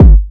Kick 3 [ feel it ].wav